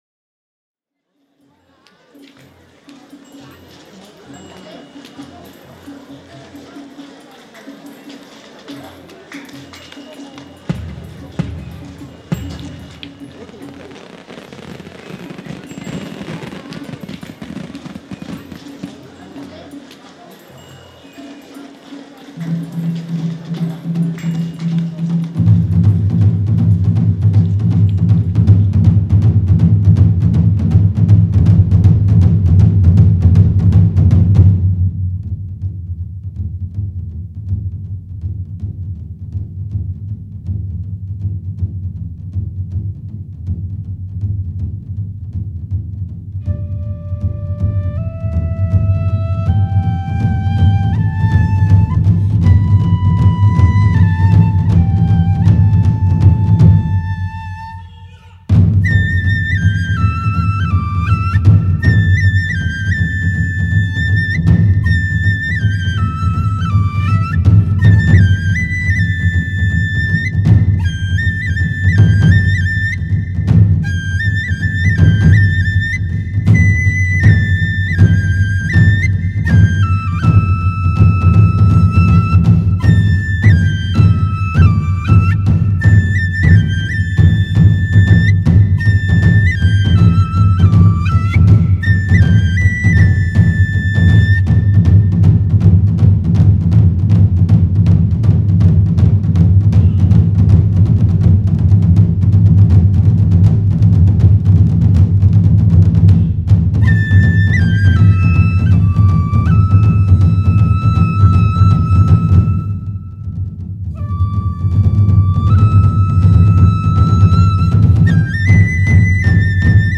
在富有节奏感的和太鼓中加入了篠笛的旋律